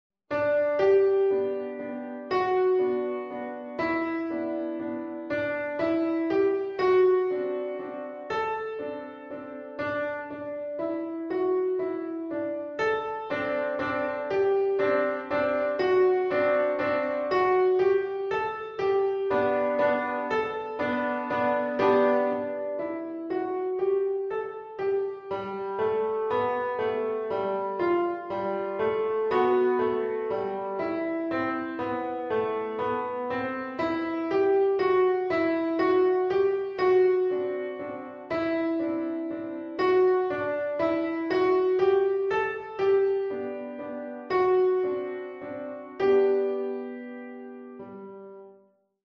Eighteen fun, original pieces for pre-grade beginners.